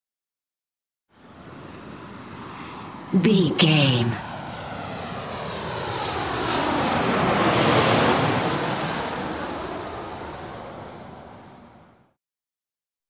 Airplane passby
Sound Effects